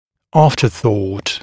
[‘ɑːftəθɔːt][‘а:фтэсо:т]запоздалая мысль